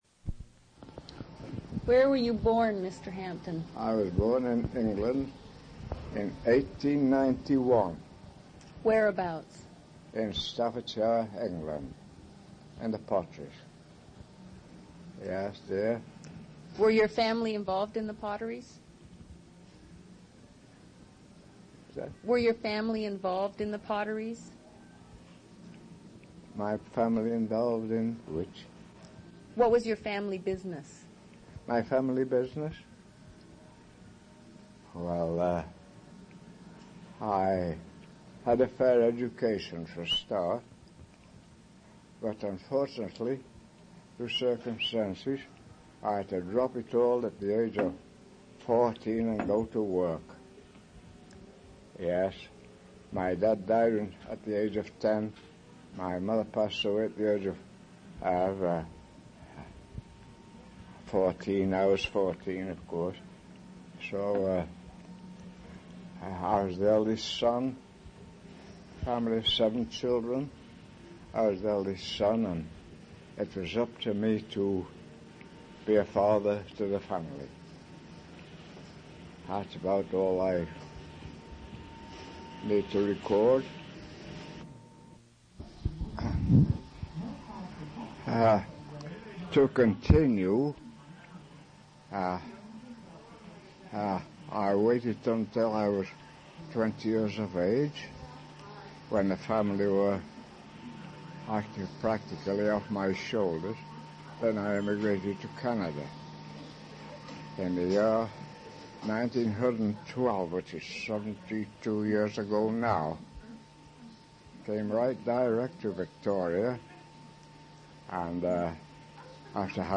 Summary is brief due to damaged cassette and incomplete transcript.
One original sound cassette (ca. 45 min.) : 2 track, mono.
oral histories (literary genre) interviews reminiscences